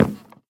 wood1.ogg